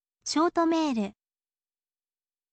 shooto meeru, โชโทะเมรึ